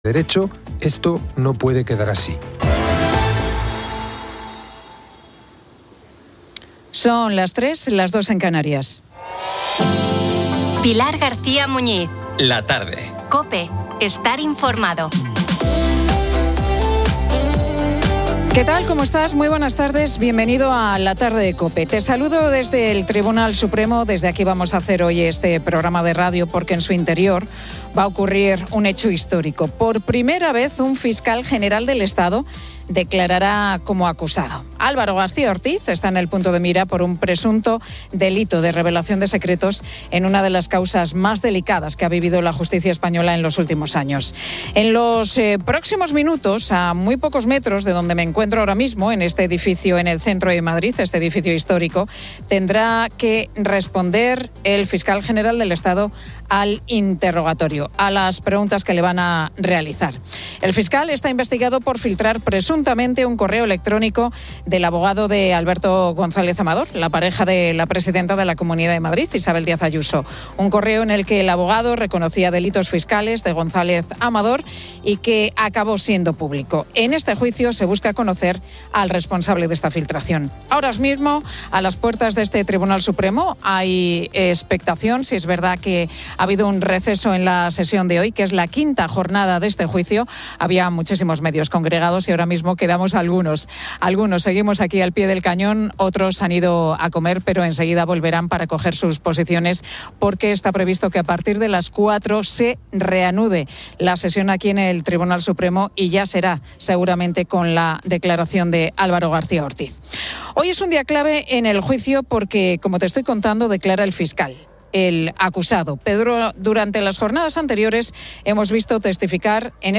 Pilar García Muñiz presenta "La Tarde" en COPE desde el Tribunal Supremo, donde el Fiscal General del Estado, Álvaro García Ortiz, declara como acusado, un hecho sin precedentes.